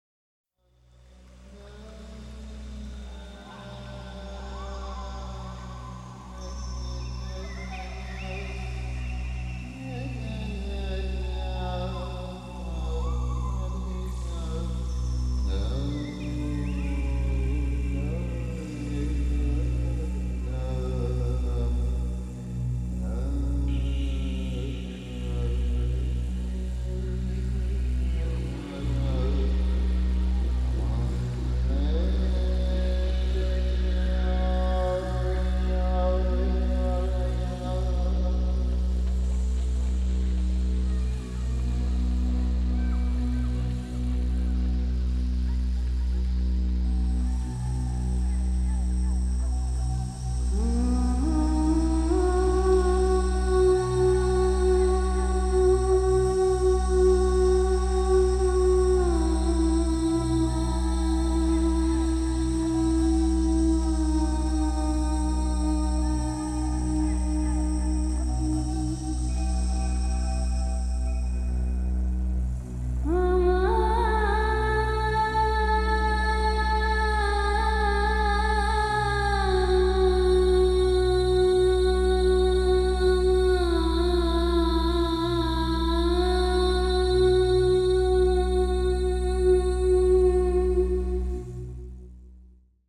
Genre: World Fusion.
traditional gypsy song